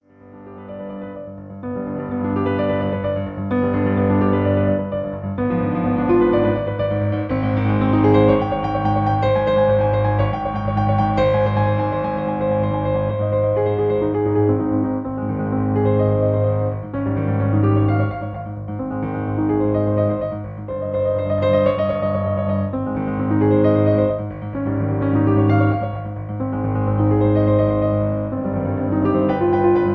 Original Key (G). Piano Accompaniment